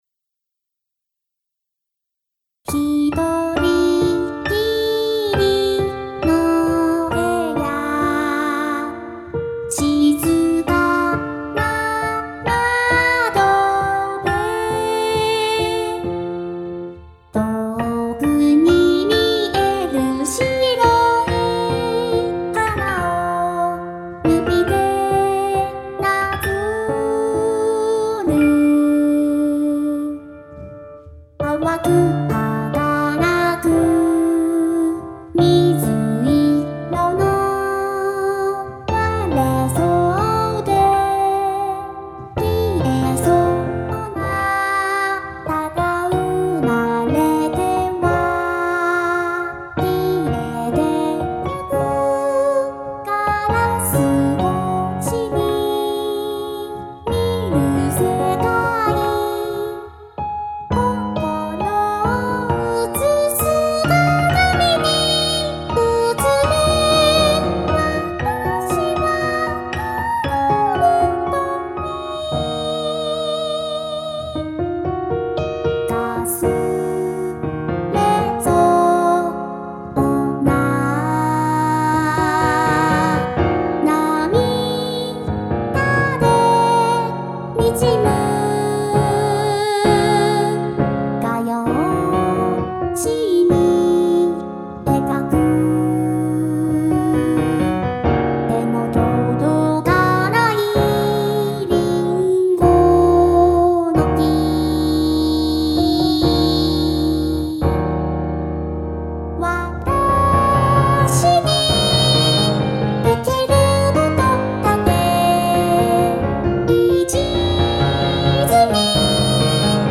ピアノ伴奏だとVoの誤魔化しが効かないので非常に難しいし、神経使いますね・・・（ーー；